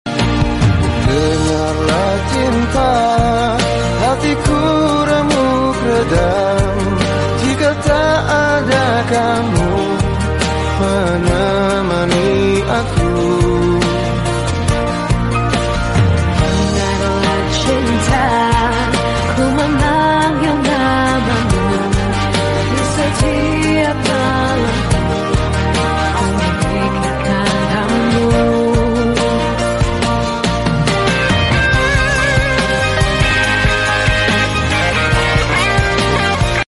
#2stroke Sound Effects Free Download